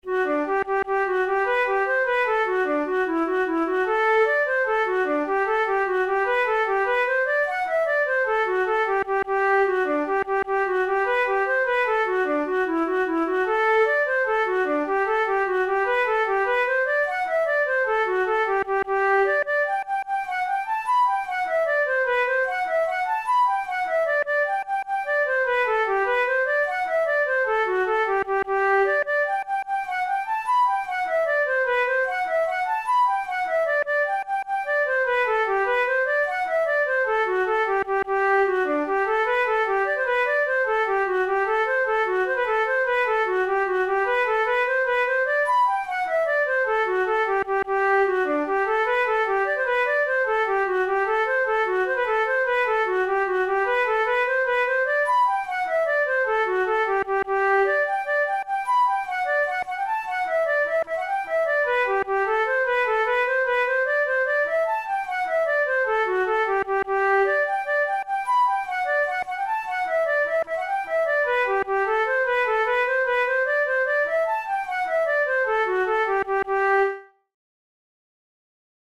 Jigs, Traditional/Folk
Traditional Irish jig